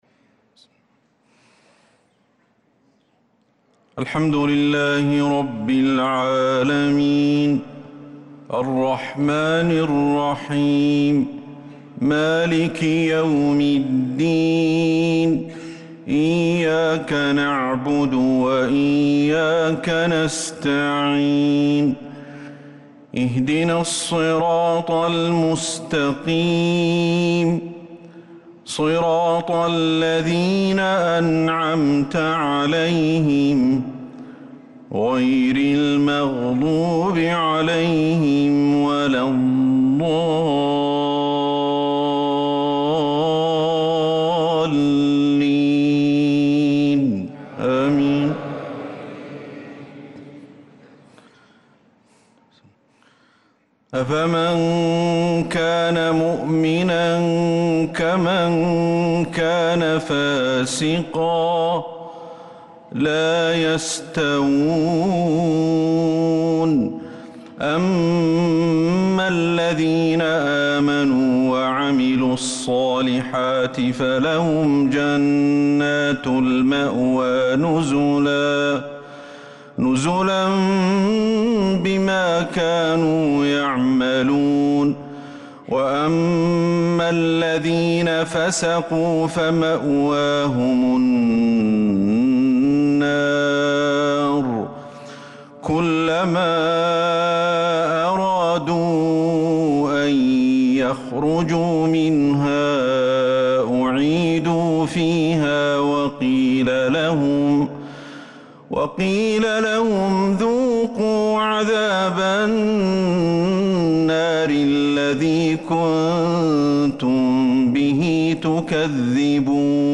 عشاء الإثنين 4-8-1446هـ خواتيم سورة السجدة 18-30 | Isha prayer from Surat AS-sajdah 3-2-2025 > 1446 🕌 > الفروض - تلاوات الحرمين